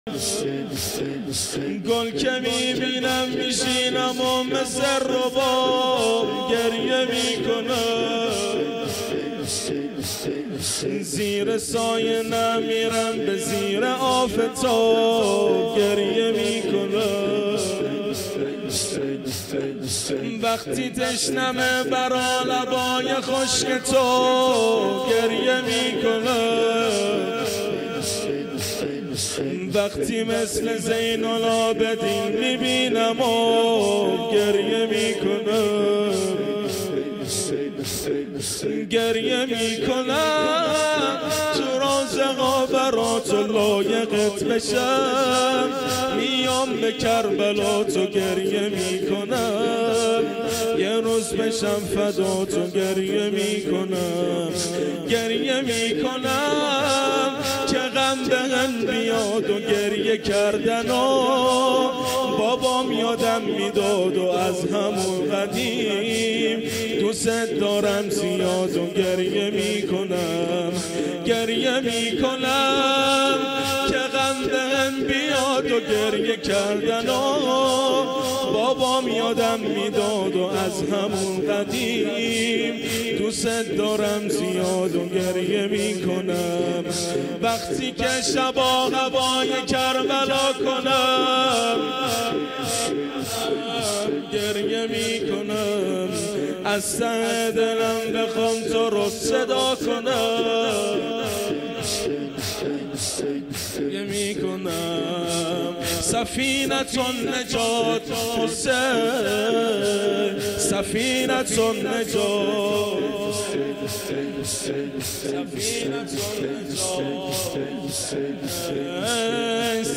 زمینه | گُل که میبینم، میشینم و مثل رباب گریه می‌کنم
مداحی
ماه رمضان 1438 هجری قمری | هیأت علی اکبر بحرین